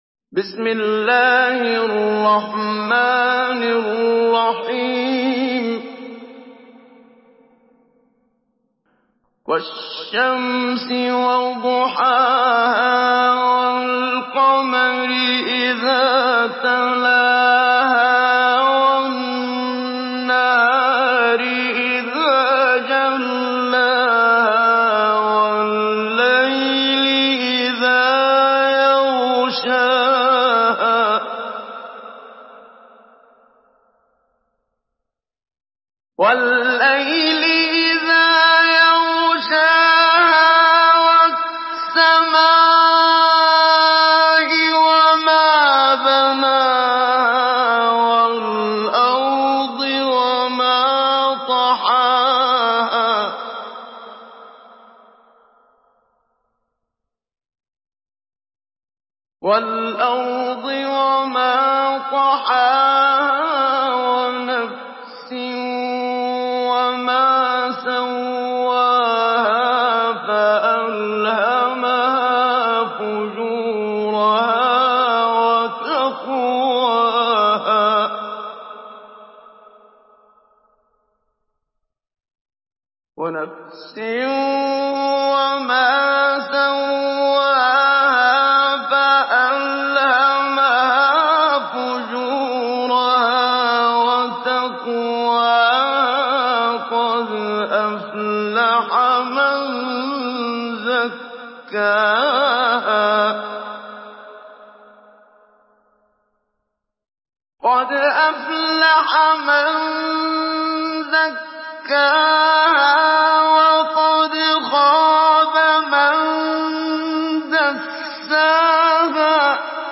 Une récitation touchante et belle des versets coraniques par la narration Hafs An Asim.
Mujawwad